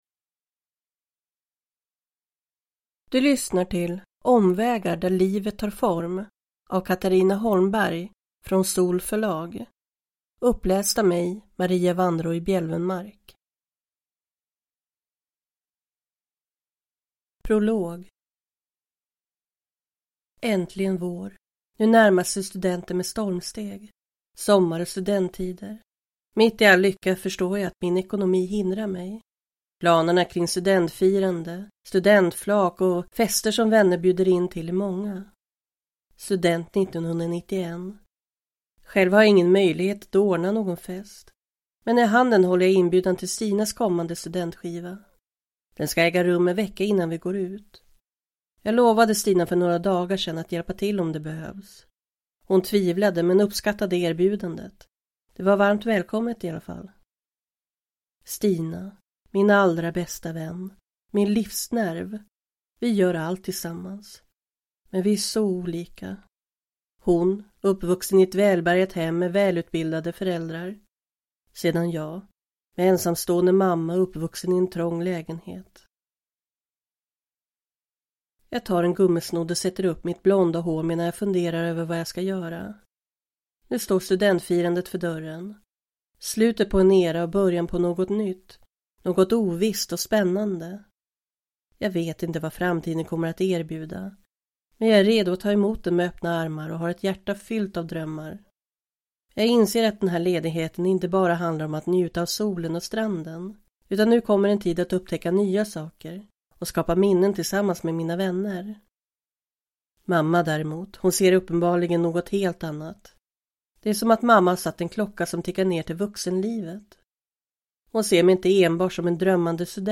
Omvägar - där livet tar form – Ljudbok